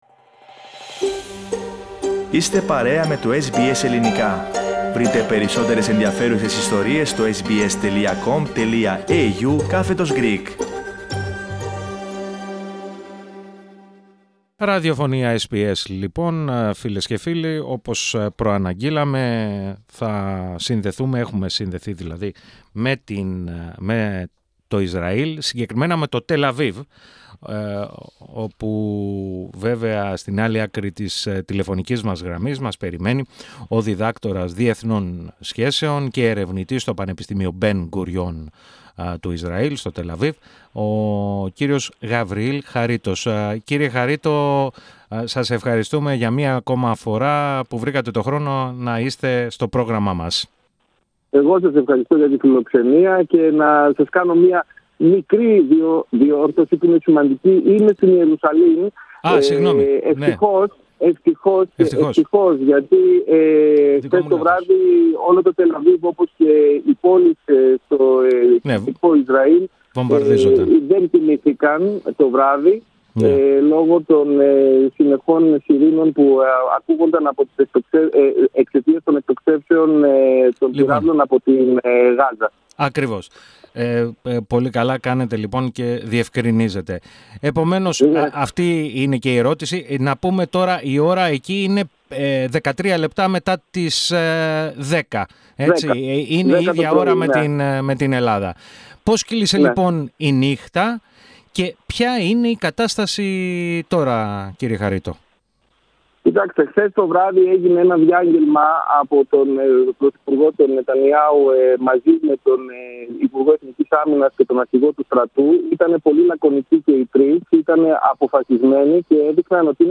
Πάντως, δεν εκτιμά, ότι οι συγκρούσεις θα οδηγήσουν σε ανοικτό πόλεμο με εισβολή Ισραηλινών δυνάμεων στη Γάζα, όπως έγινε το 2014, χωρίς βέβαια να αποκλείεται κανένα ενδεχόμενο. Επιπλέον, σημείωσε το γεγονός ότι και η Χαμάς, φαίνεται να είναι καλύτερα εξοπλισμένη απ' ότι το 2014. Ακούστε ολόκληρη τη συνέντευξη, πατώντας το σύμβολο στο μέσο της κεντρικής φωτογραφίας.